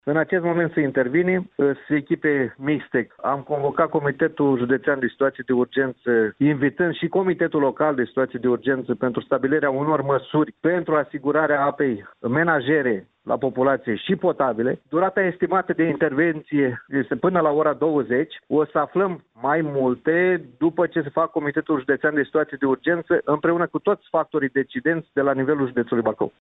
Echipele Companiei Regionale de Apă Bacău se află la faţa locului pentru a evalua şi remedia defecţiunea iar situaţia ar putea reveni la normal diseară, după cum a declarat pentru postul nostru de radio, prefectul de Bacău, Valentin Ivancea.